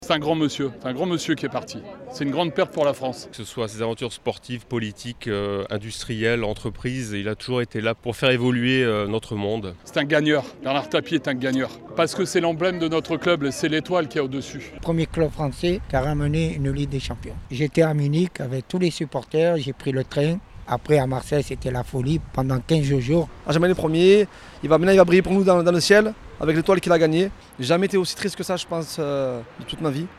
Dimanche 3 octobre après-midi, ils étaient déjà réunis devant le Vélodrome.
Dans la foule bleue et blanche sur le parvis du stade, beaucoup n’étaient même pas nés pendant les années Tapie à l’OM, mais peu importe.